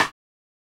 SNARE CRISPY.wav